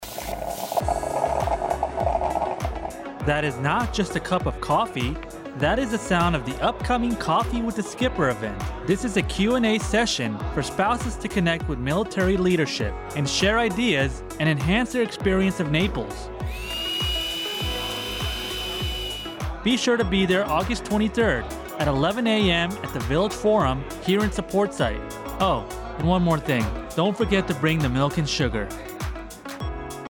AFN Naples Radio Spot - Coffee With The Skipper